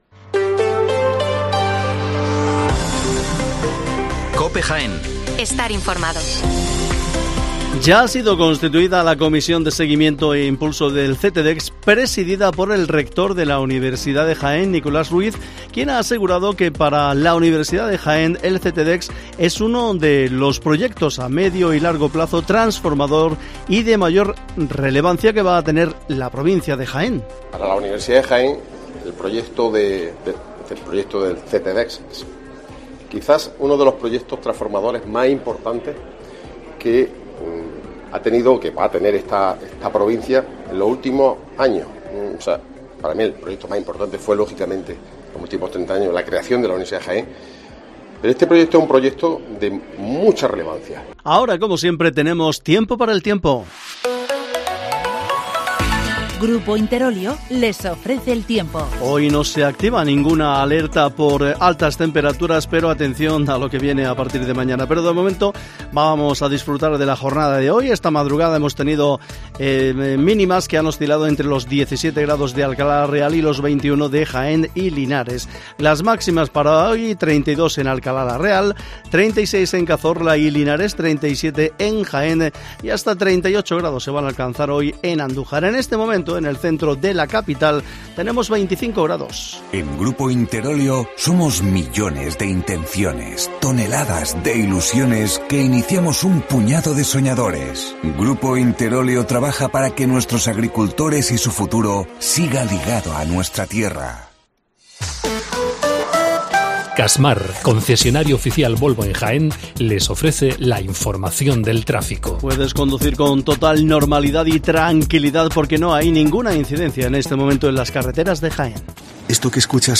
Las noticias matinales en Herrera en COPE 8:24 horas